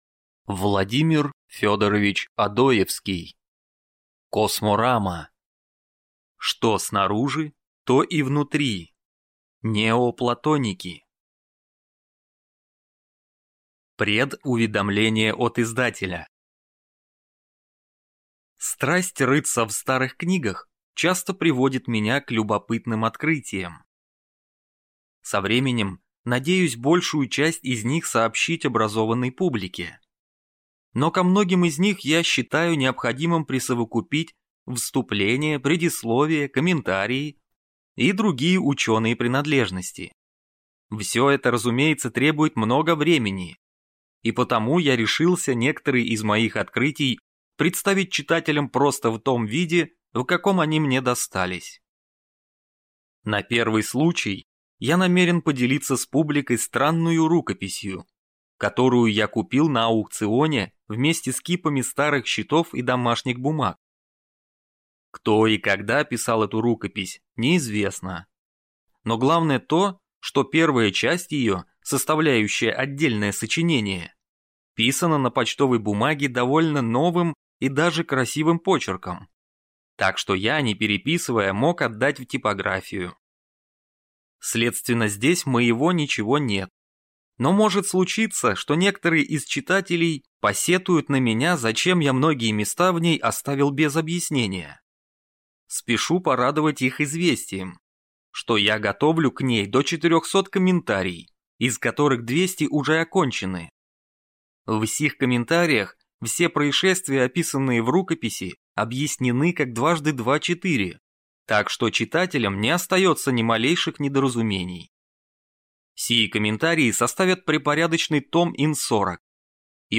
Аудиокнига Косморама | Библиотека аудиокниг